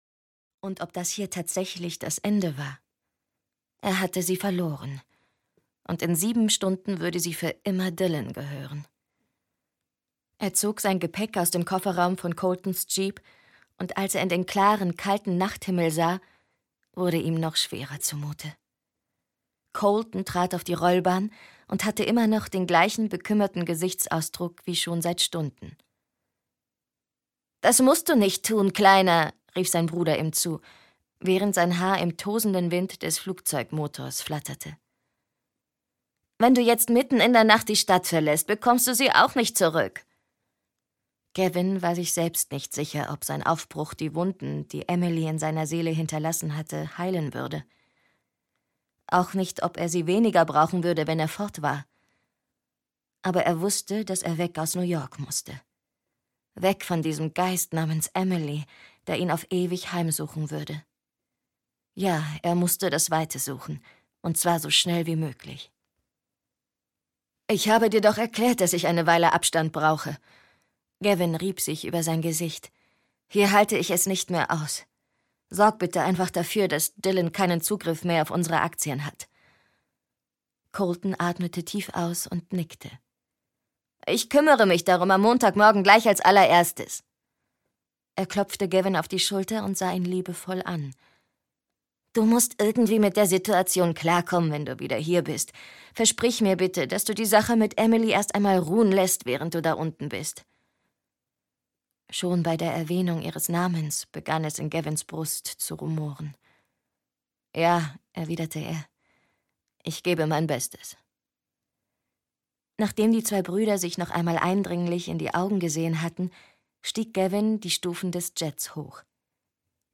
Collide-Serie 2: Pulse - Unzertrennlich - Gail McHugh - Hörbuch